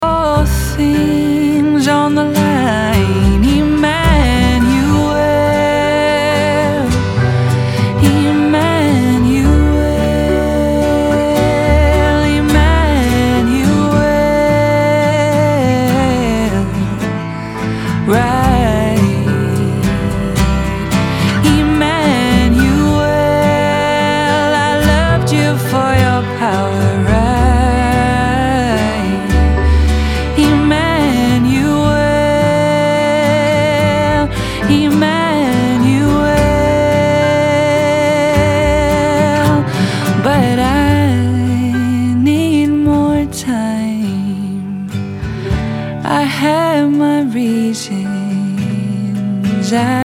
Indie Rock, Indie Pop >
Folk Rock, Acoustic >